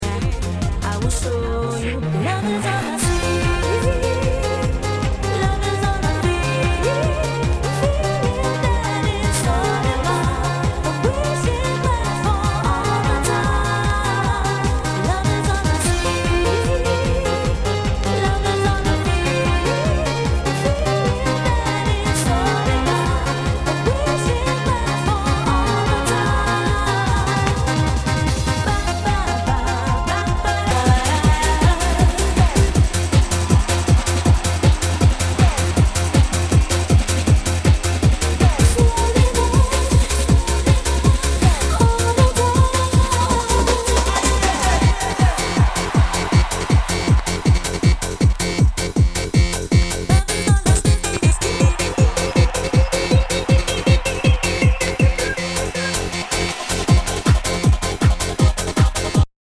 HOUSE/TECHNO/ELECTRO
ナイス！ユーロ・ヴォーカル・ハウス！